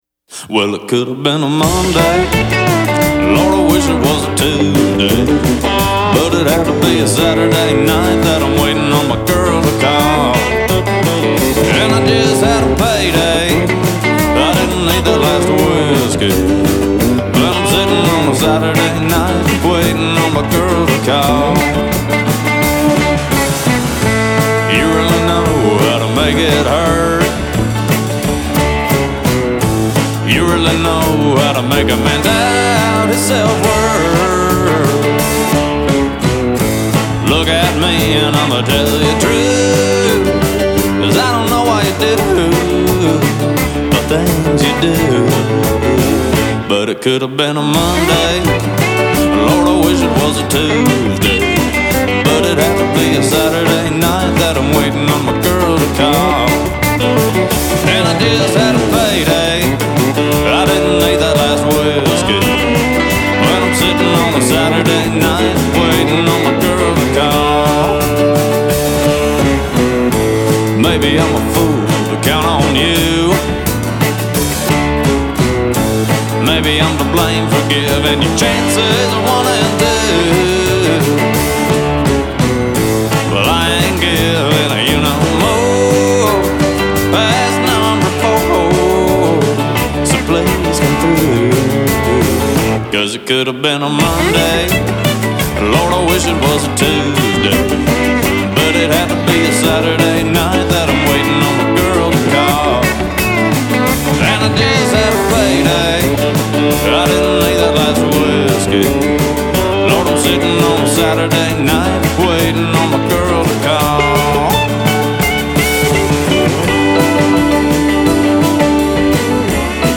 contribute accordion parts on the album